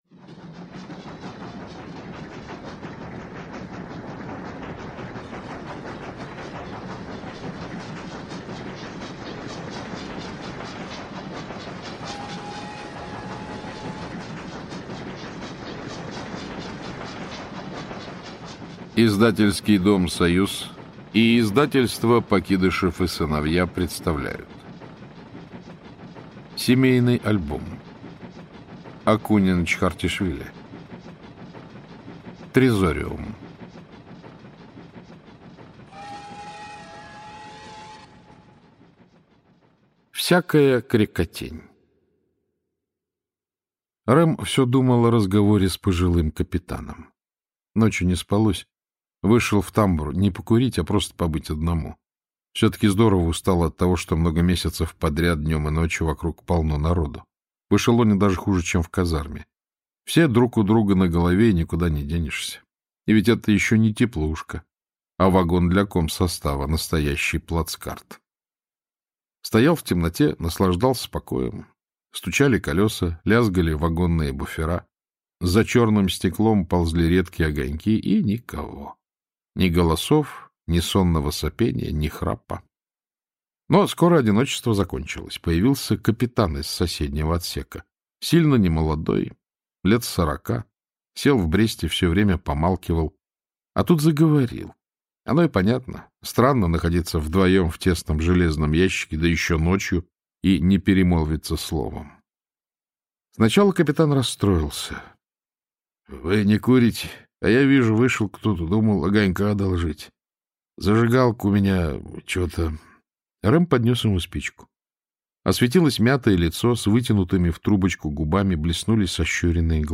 Аудиокнига Трезориум - купить, скачать и слушать онлайн | КнигоПоиск